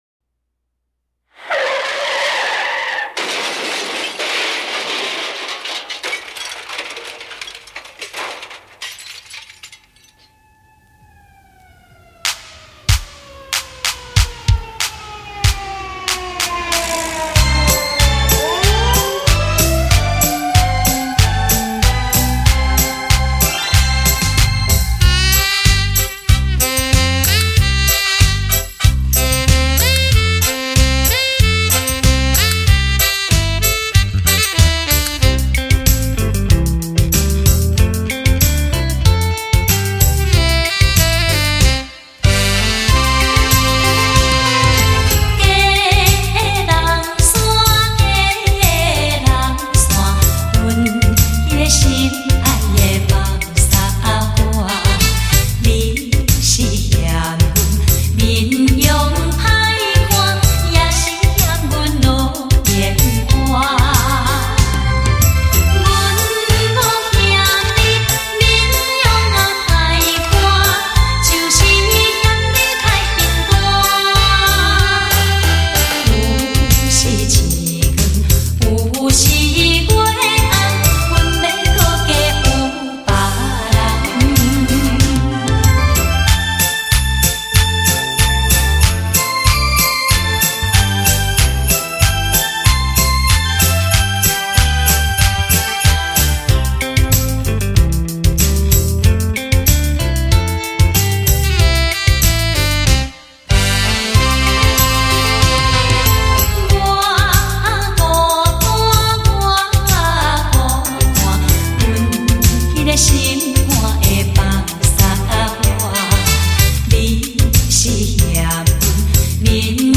伦巴